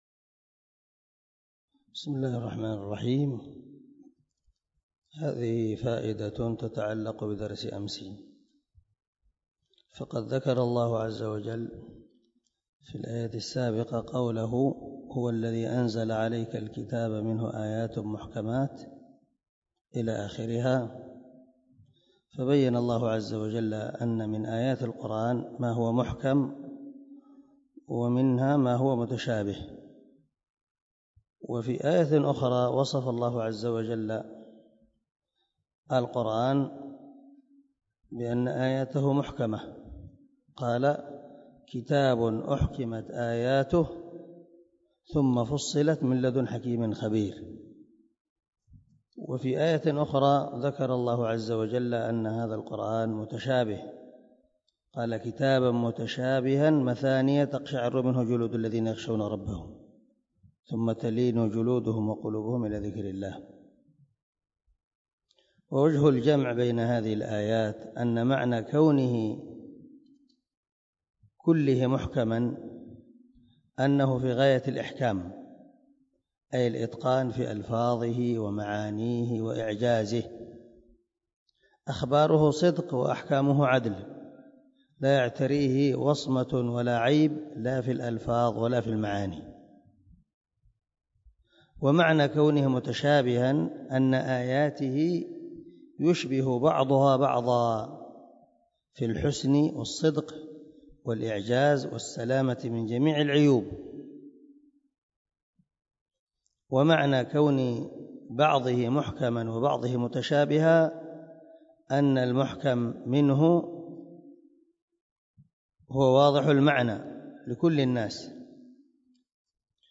دار الحديث- المَحاوِلة- الصبيحة.